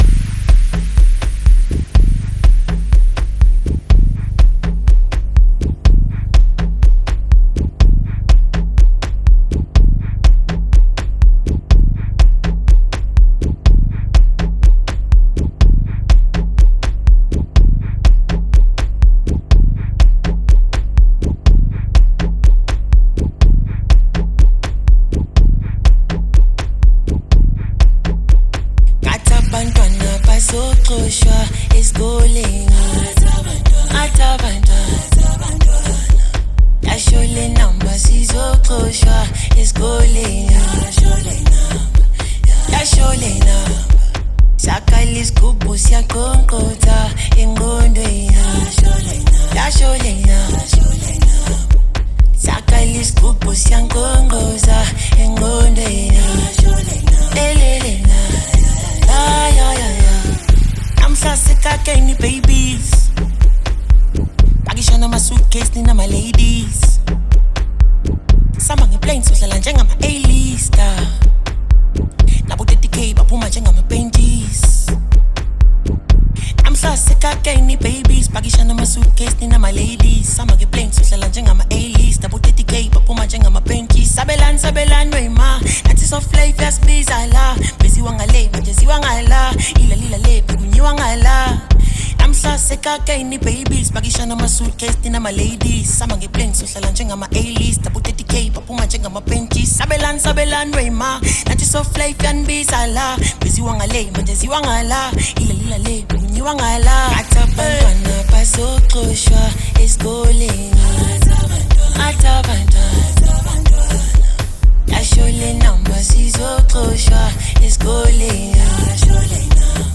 Gqom banger